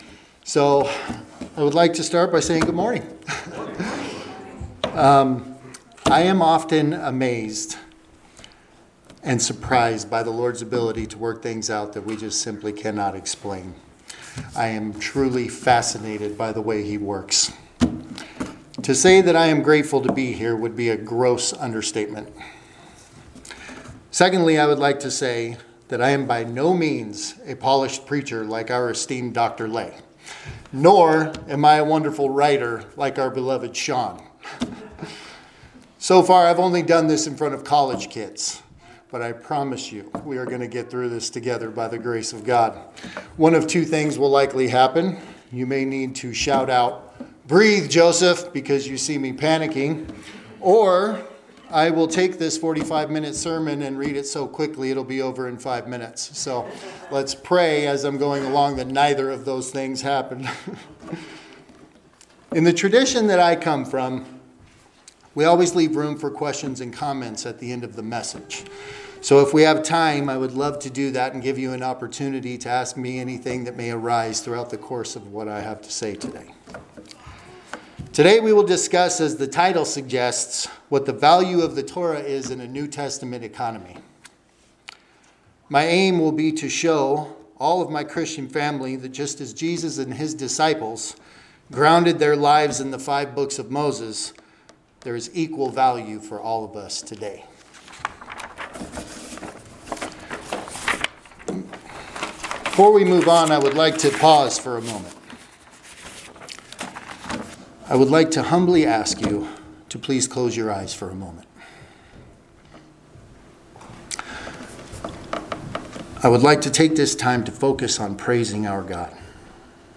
Passage: Romans 9: 1-5 Service Type: Sunday Morning Worship Topics